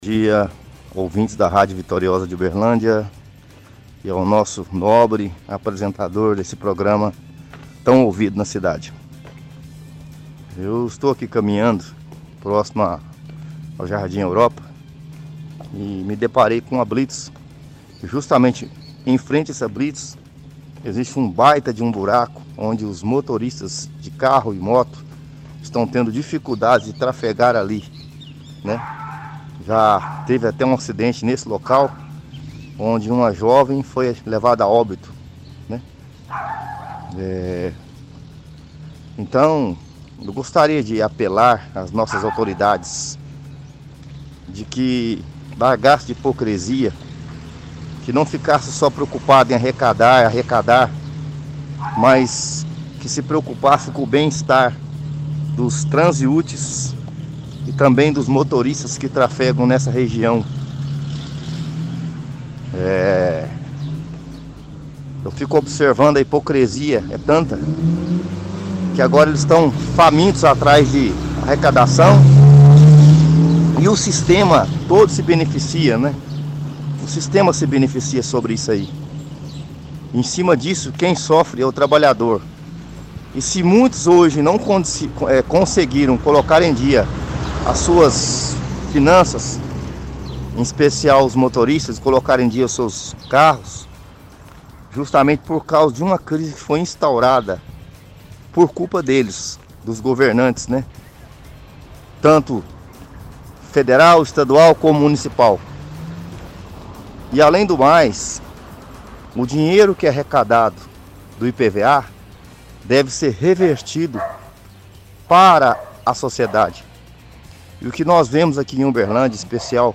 – Ouvinte reclama da quantidade de multas e impostos em Uberlândia.